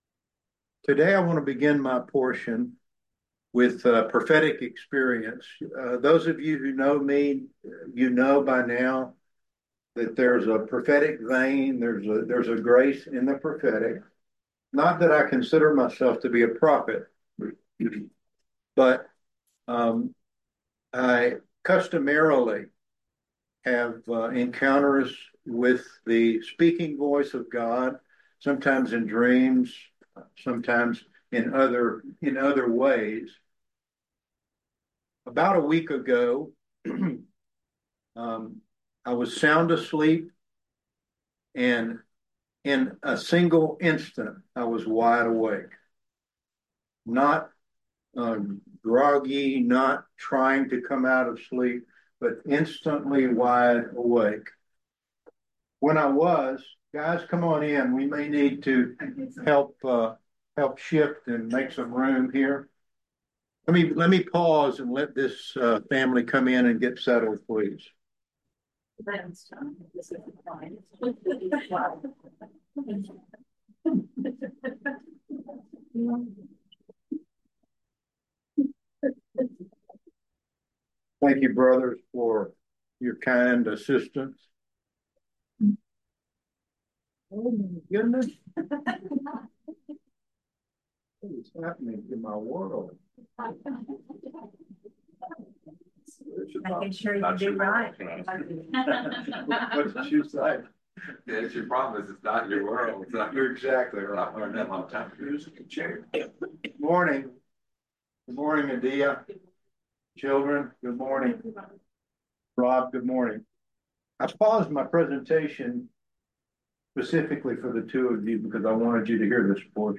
We had a wonderful gathering on Sunday, March 17th.